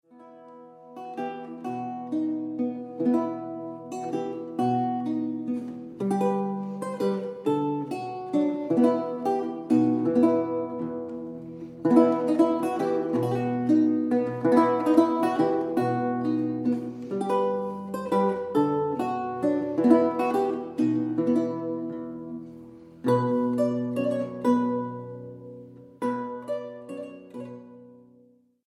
Englische Lautenmusik des 16. Jahrhunderts
Laute